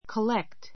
kəlékt